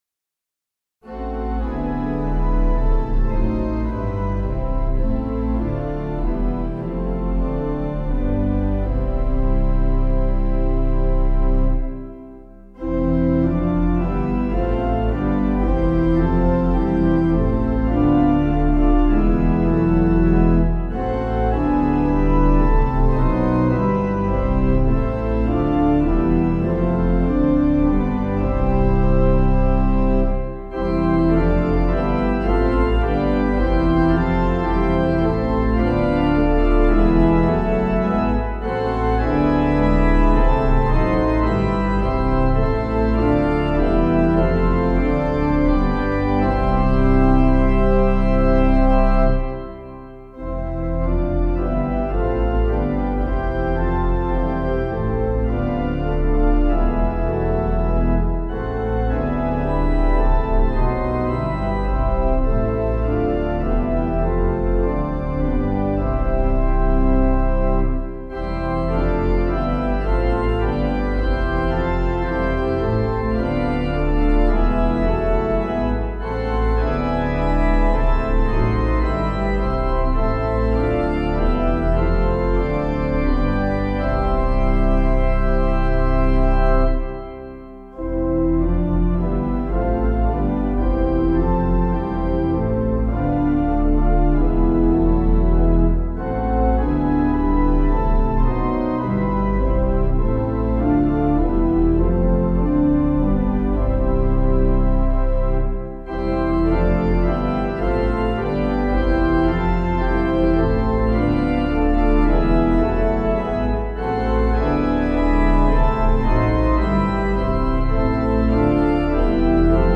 Information about the hymn tune REFUGE (Buck).
Key: D♭ Major